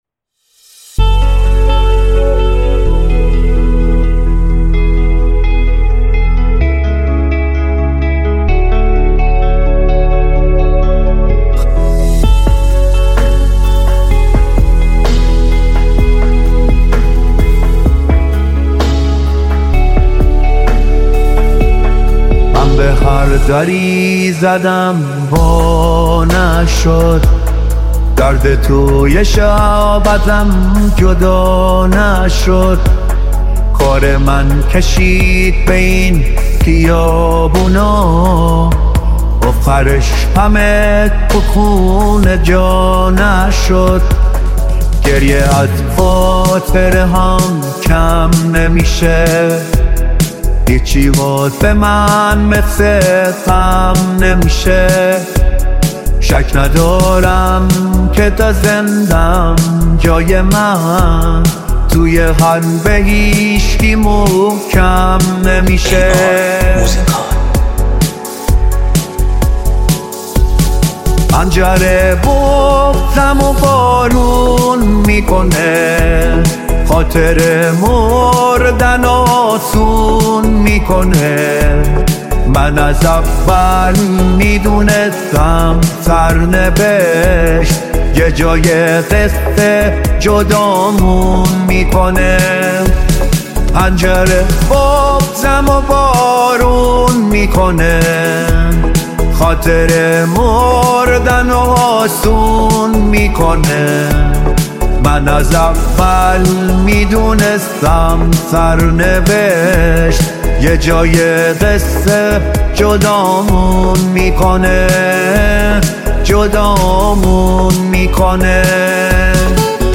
پاپ ایرانی
آهنگ های هوش مصنوعی